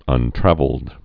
(ŭn-trăvəld)